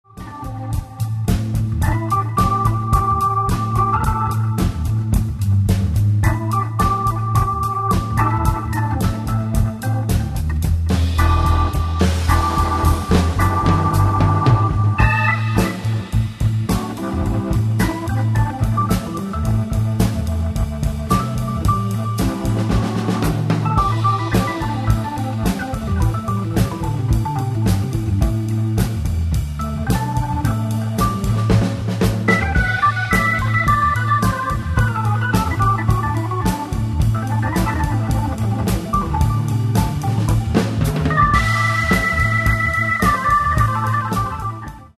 - Forum du son Hammond
J'ai l'impression que le thème est la reprise d'un morceau de R'n'B (le choix est vaste) qui se décline ensuite dans un style plus jazzy. Et ça groove.
J'adore ce son brut mais ça ne fait peut-être pas CD du commerce.